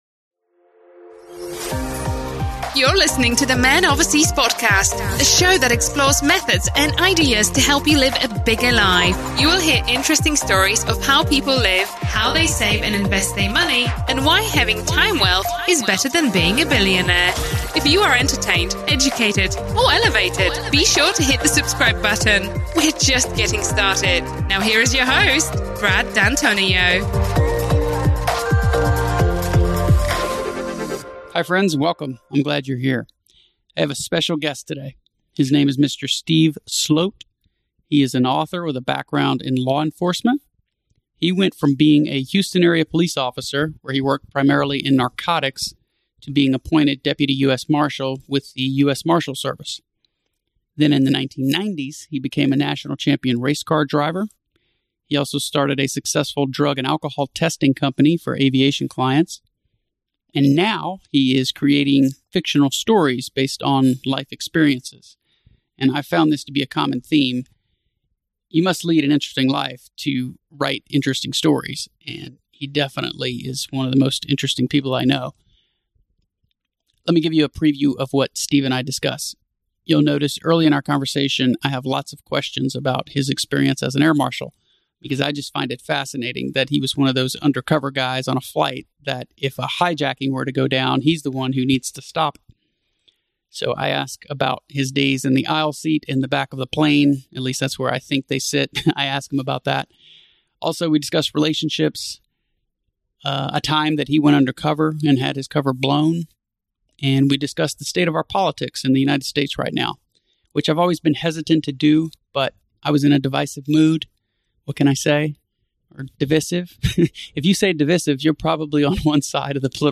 But decided I wanted to interview him in person (I was out of the country most of last year).